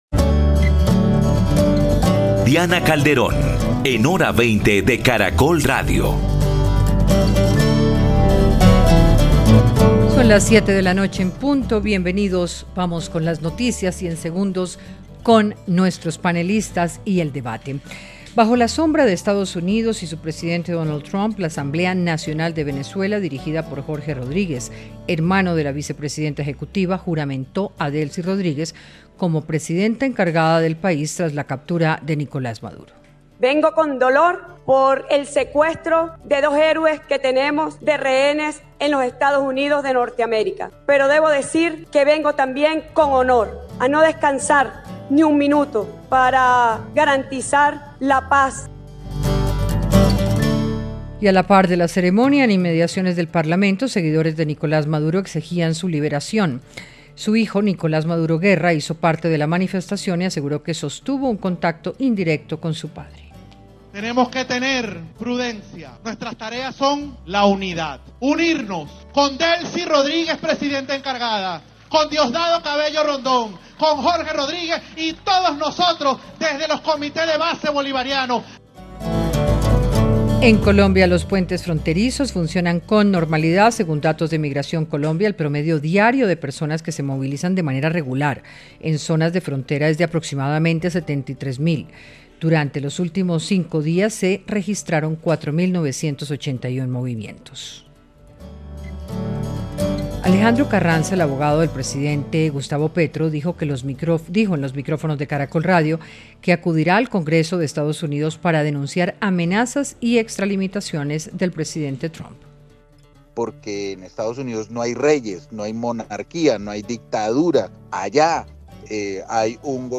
Panelistas analizaron el miedo que se ha apoderado de los venezolanos, las expresiones de represión y el futuro de la presidencia interina de Delcy Rodríguez.